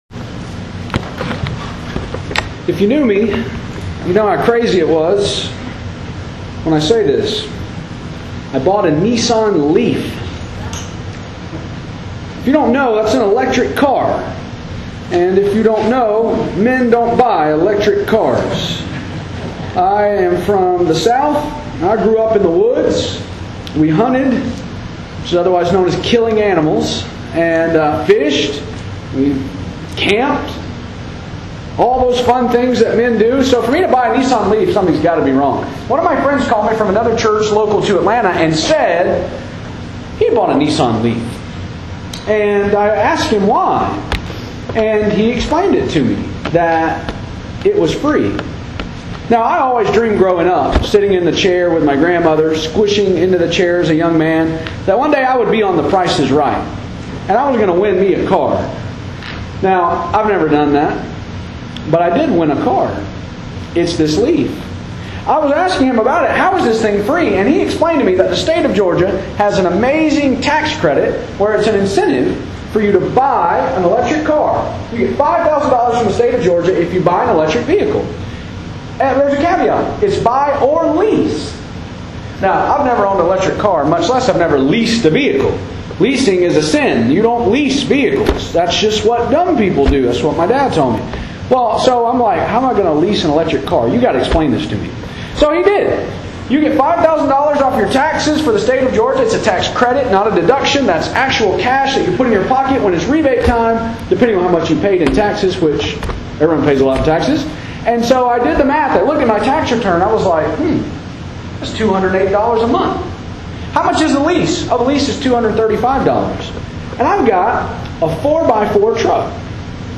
Speaking Preacher